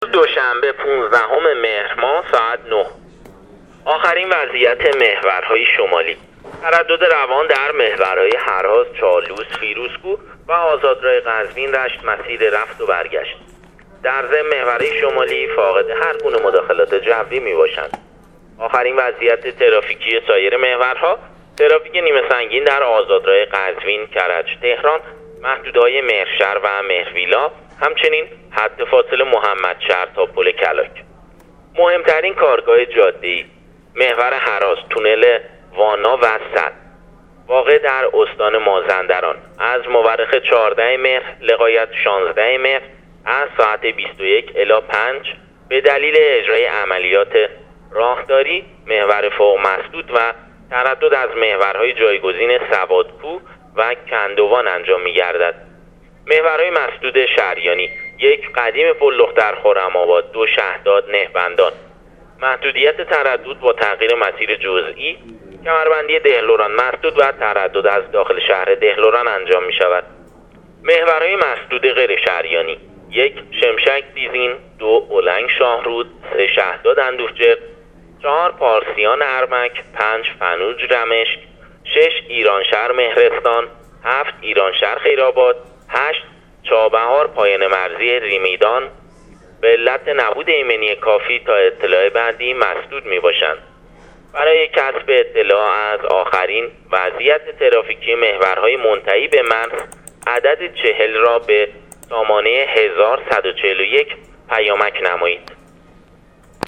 گزارش رادیو اینترنتی پایگاه خبری وزارت راه و شهرسازی از آخرین وضعیت ترافیکی جاده‌های کشور تا ساعت ۹ پانزدهم مهر/ترافیک نیمه‌سنگین در محور قزوین-کرج-تهران